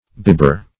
Search Result for " bibber" : The Collaborative International Dictionary of English v.0.48: Bibber \Bib"ber\, n. One given to drinking alcoholic beverages too freely; a tippler; -- chiefly used in composition; as, winebibber.